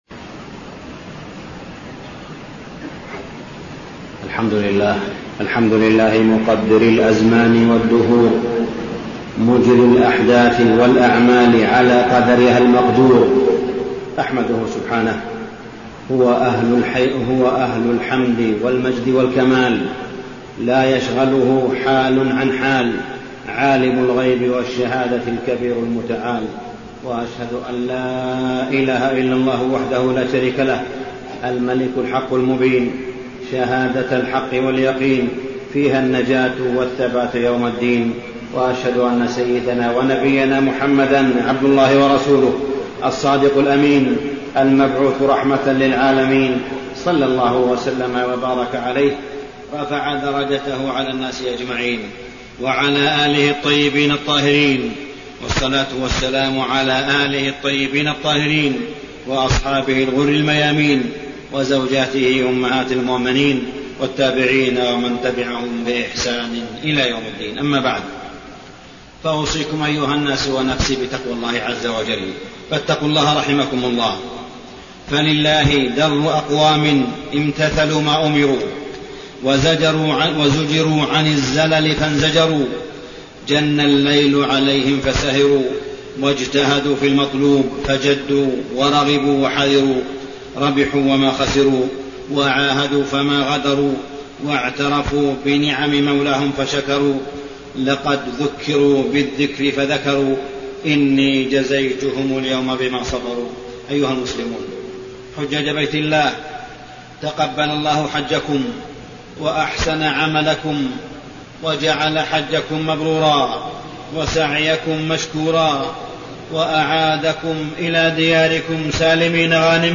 تاريخ النشر ١٤ ذو الحجة ١٤٢٩ هـ المكان: المسجد الحرام الشيخ: معالي الشيخ أ.د. صالح بن عبدالله بن حميد معالي الشيخ أ.د. صالح بن عبدالله بن حميد أسرار الحج وحكمه وكلمة للحجيج